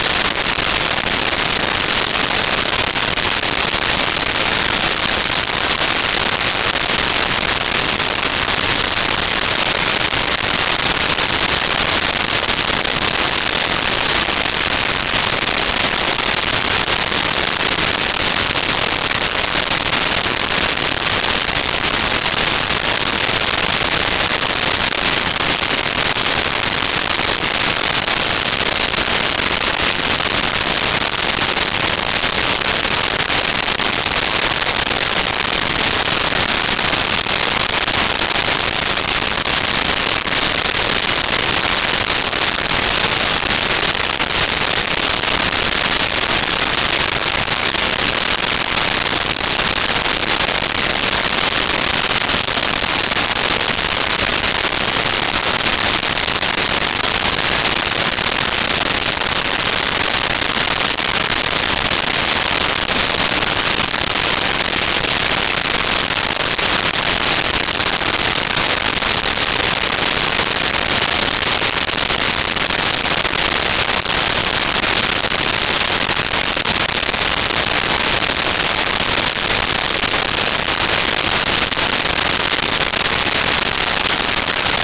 Rain Heavy 01
Rain Heavy 01.wav